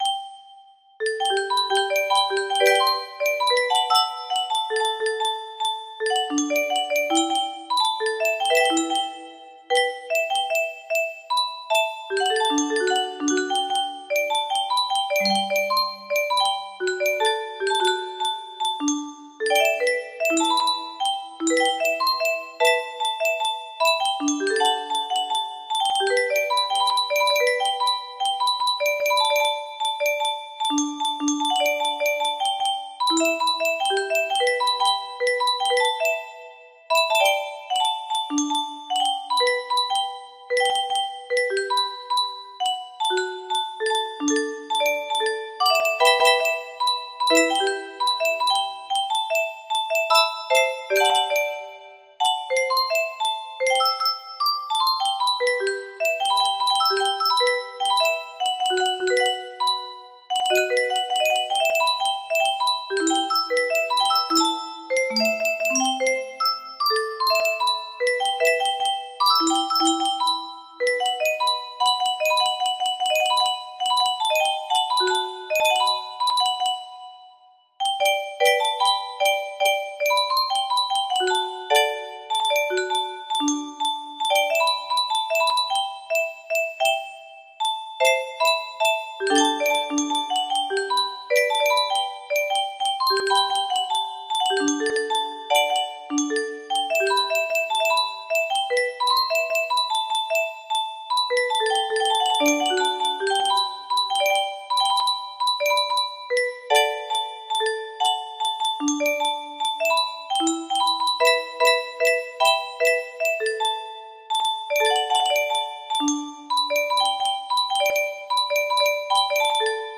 04-sns-ppl-bstr music box melody